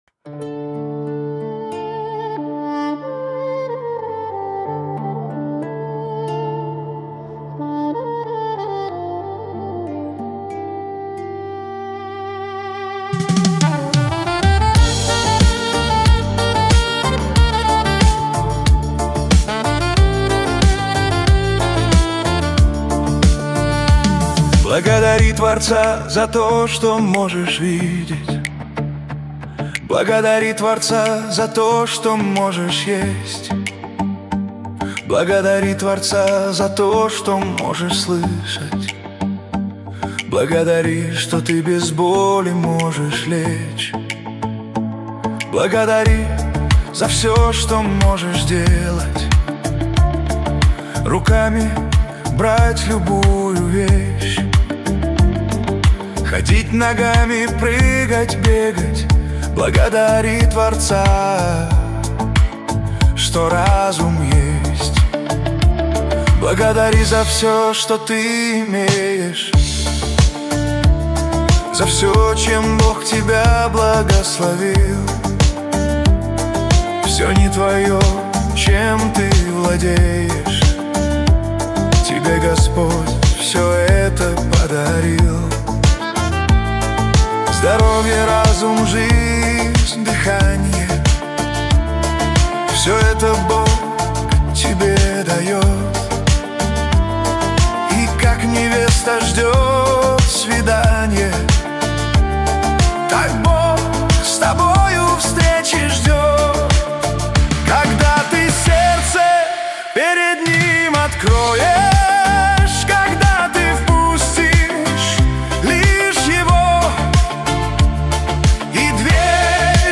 песня ai
1171 просмотр 1100 прослушиваний 453 скачивания BPM: 92